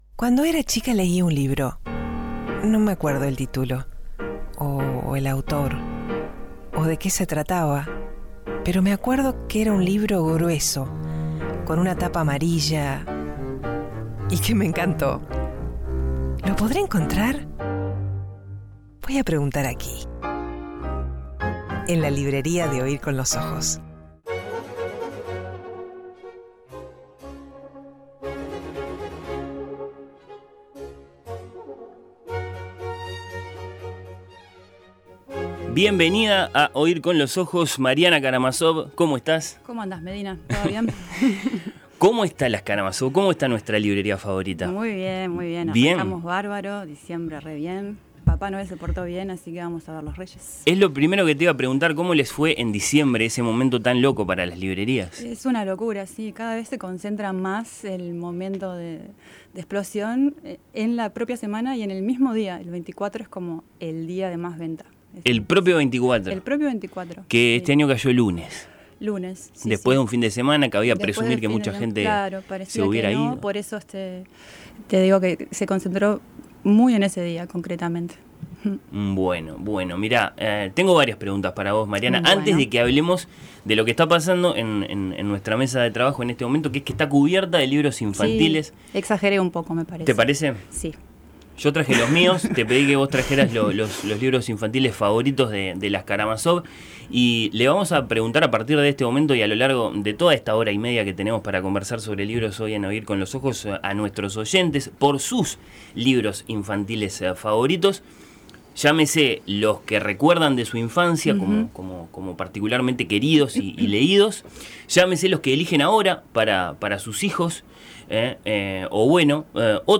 La selección: Flotante Caracúlico La floresta raíz laberinto Cuentos en verso para niños perversos Amigos por el viento Agujero Cuentos del mundo Música: un fragmento de la Sinfonía de juguetes, atribuida a Leopold Mozart.